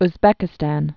(z-bĕkĭ-stăn, -stän, ŭz-) or Formerly also Uzbek Soviet Socialist Republic.